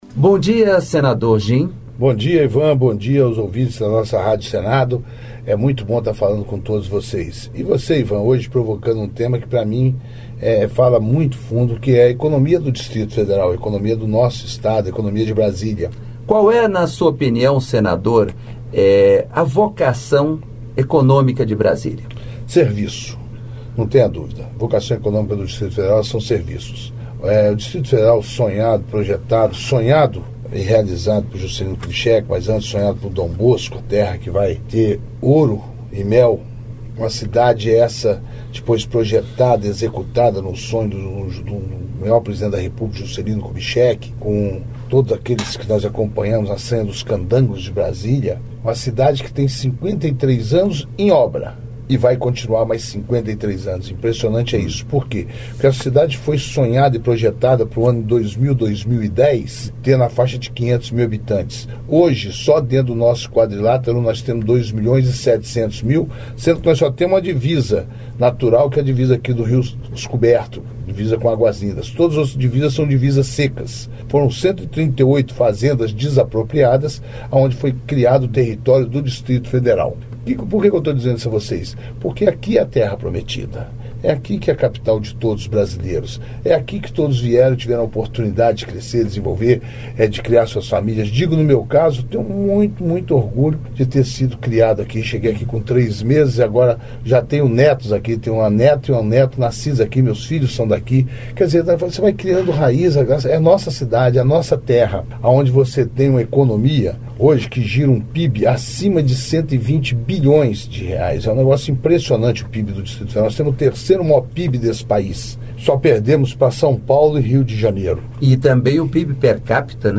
Entrevista com o senador Gim Argello (PTB-DF).